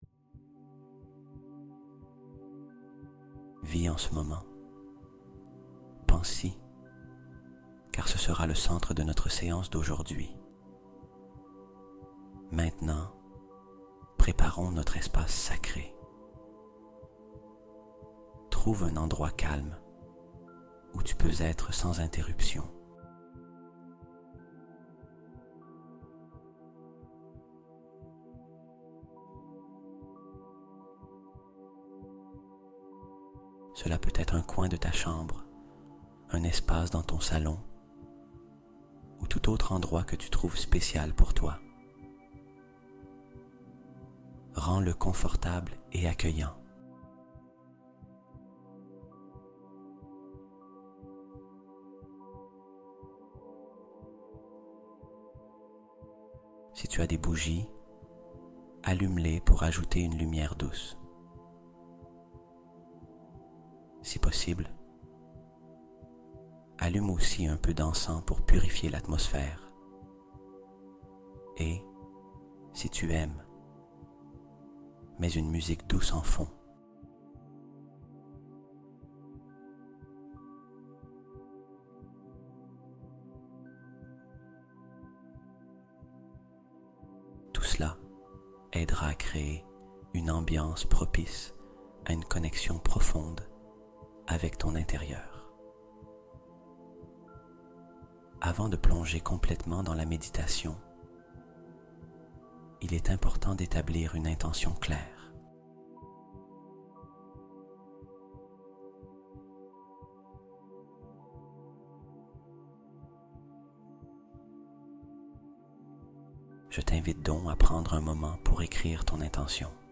1111Hz ALERTE DIVINE ACTIVÉE | Miracles + Guérison + Amour Arrivent Dans Les Prochaines 48 Heures